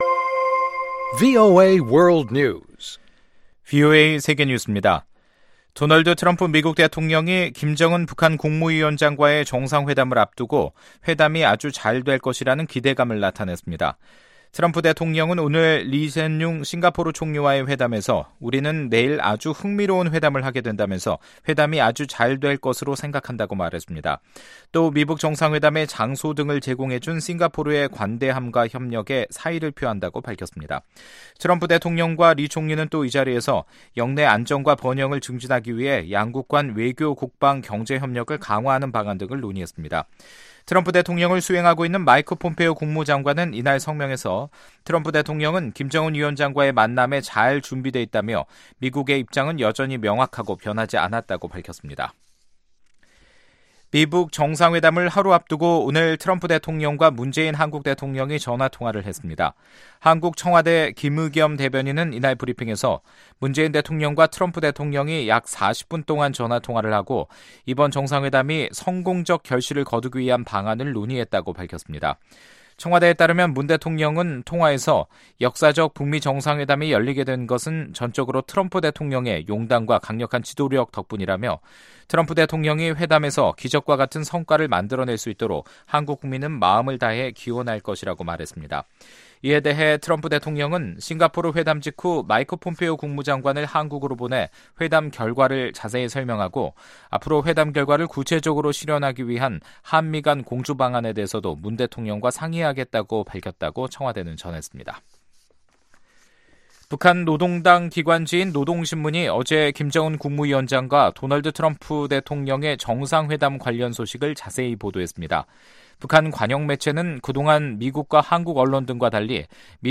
VOA 한국어 간판 뉴스 프로그램 '뉴스 투데이', 2018년 6월 11일2부 방송입니다. 미-북 정상회담이 하루 앞으로 다가온 가운데 미국은 완전하고 검증가능하며 되돌릴 수 없는 비핵화를 계속 강조하고 있습니다. 마이크 펜스 부통령은 트럼프 대통령이 미-북 정상회담에서 시간을 낭비하지 않으며 과거의 실수도 반복하지 않을 것이라고 밝혔습니다.